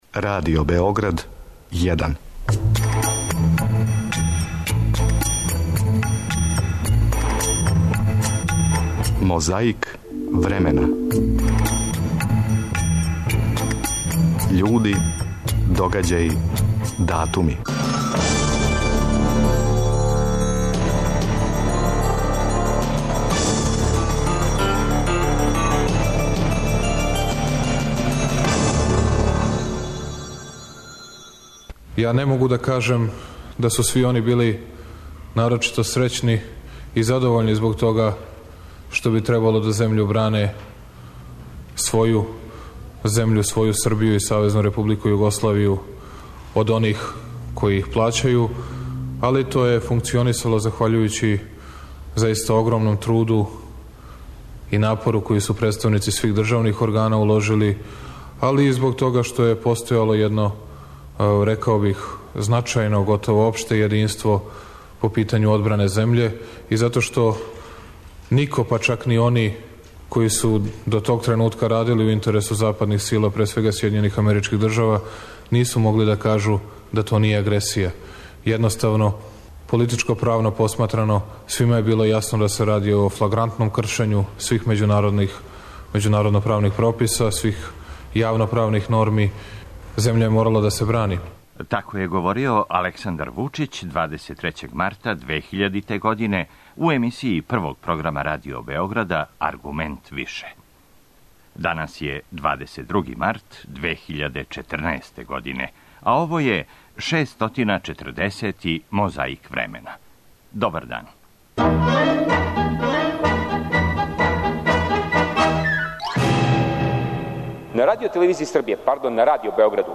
На таласима Првог програма Радио Београда 23. марта 2000. пловила је емисија Аргумент више, а у емисији: двојица питају, један одговара.